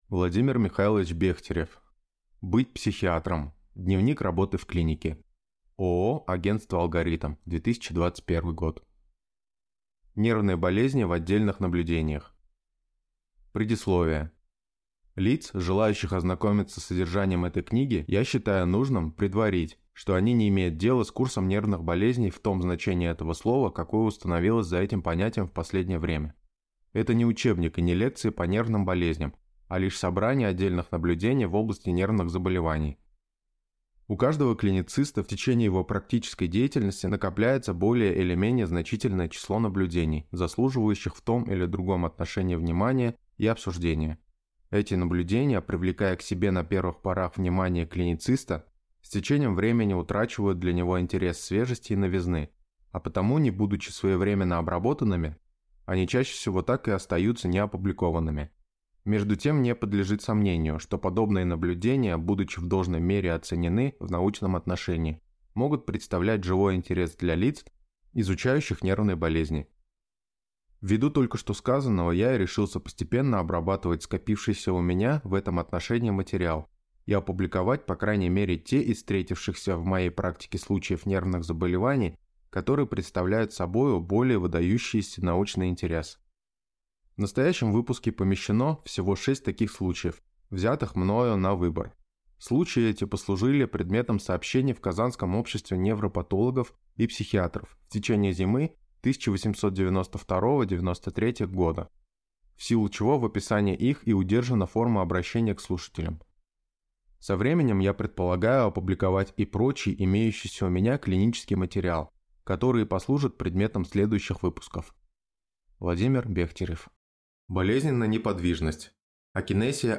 Аудиокнига Быть психиатром. Дневник работы в клинике | Библиотека аудиокниг